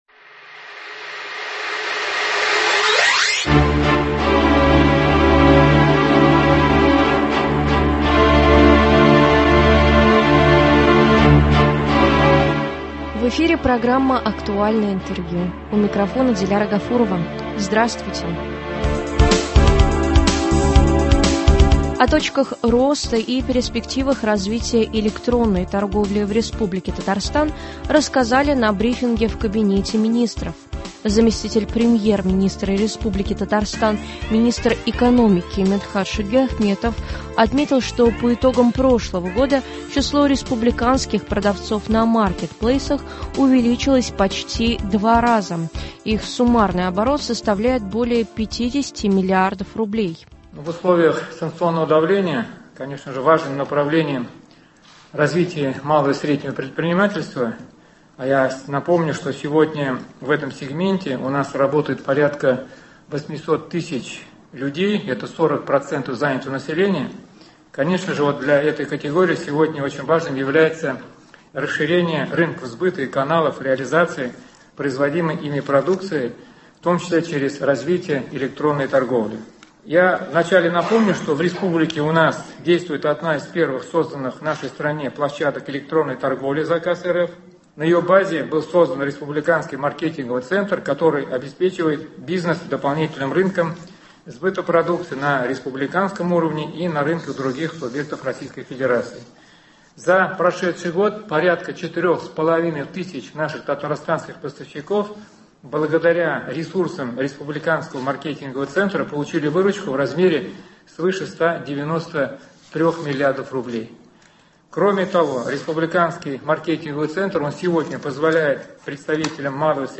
Актуальное интервью (01.03.23)